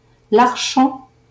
Larchant (French pronunciation: [laʁʃɑ̃]